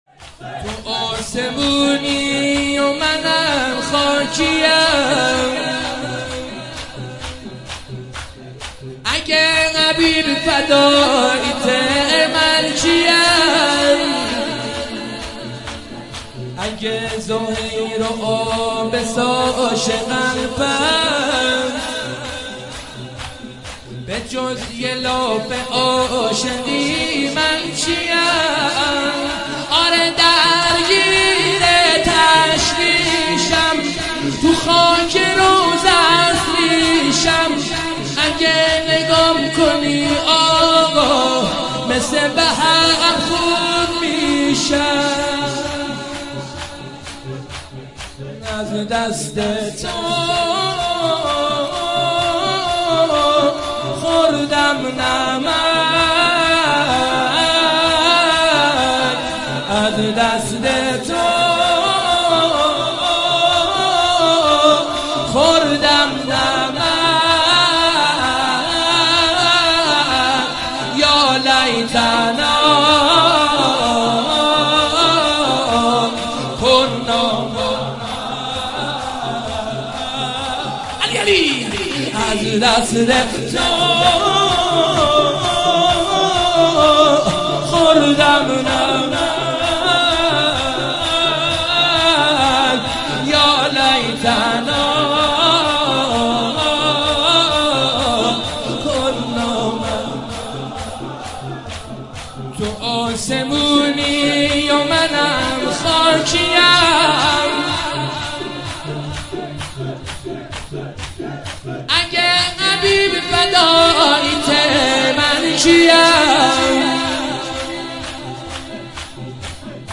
تو آسمونی و منم خاکی ام _ شور
شب چهارم محرم